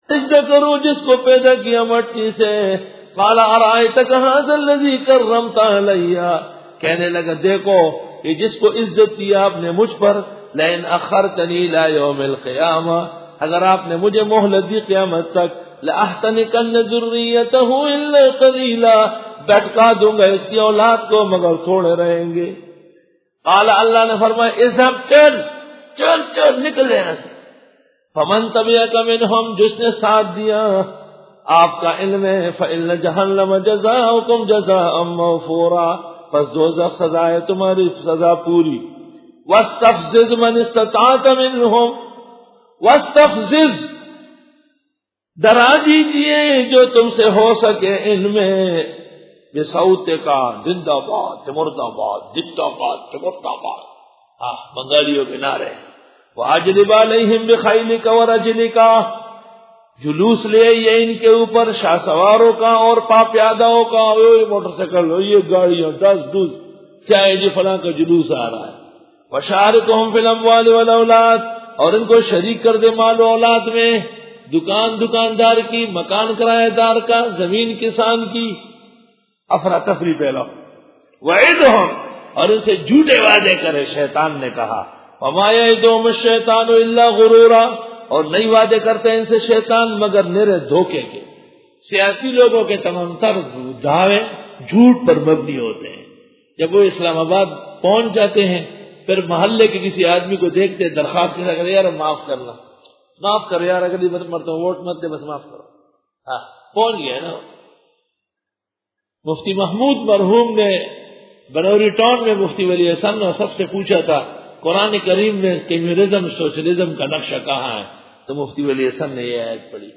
سورۃ بنی اسرائیل رکوع 07 Bayan